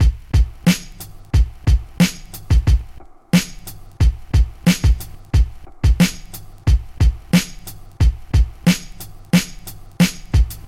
描述：嘻哈鼓
Tag: 90 bpm Hip Hop Loops Drum Loops 1.80 MB wav Key : Unknown